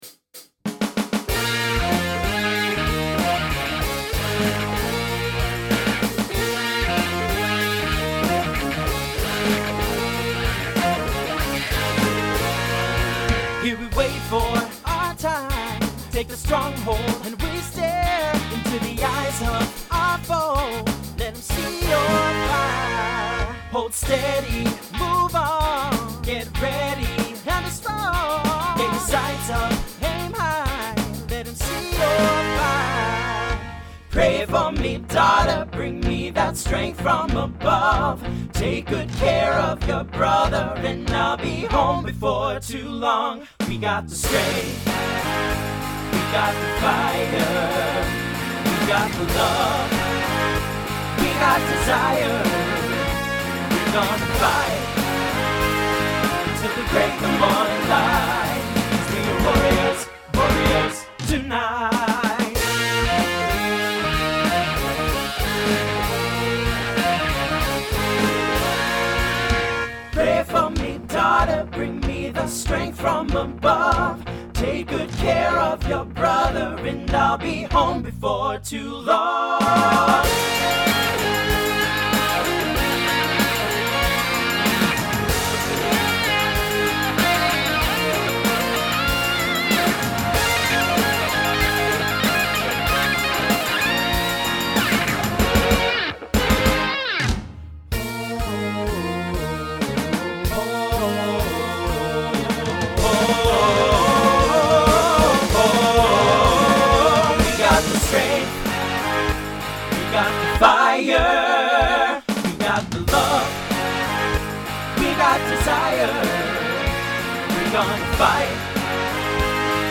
Voicing TTB Instrumental combo Genre Rock
Mid-tempo